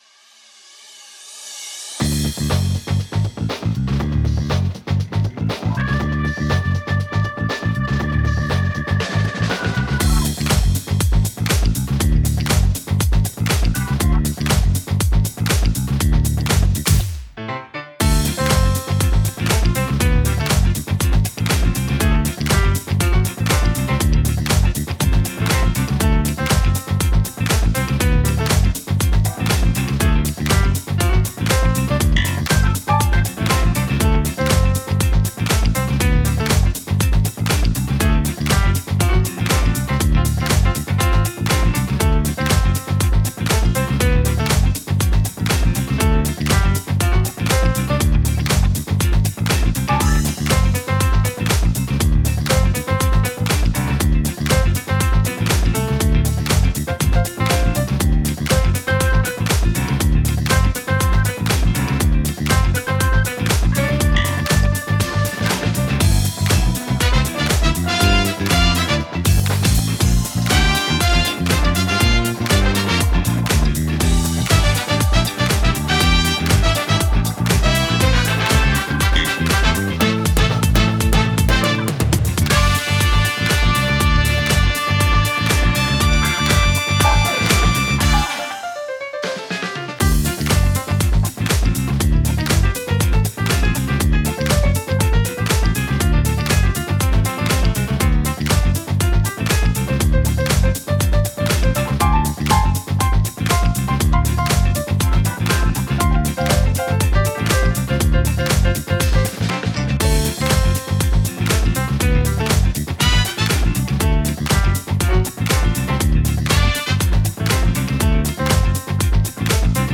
Deeply sampled grand piano with a rich, versatile tone.